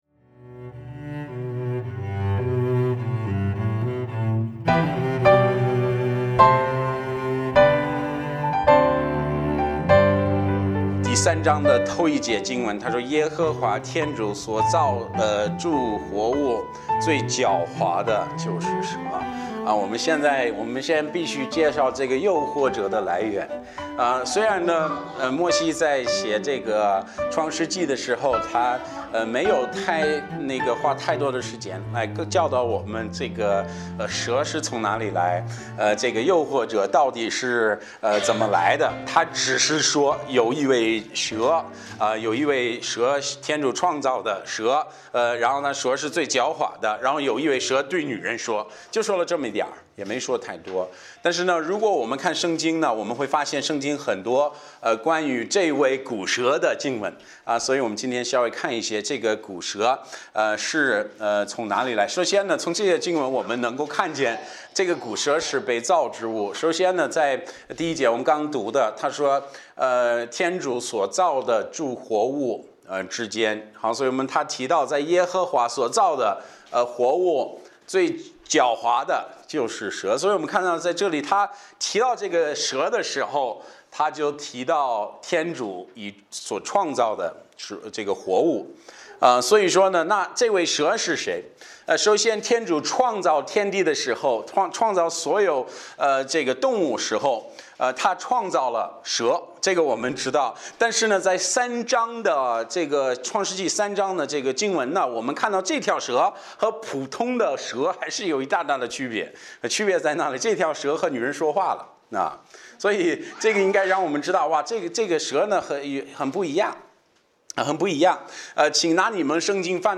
牧师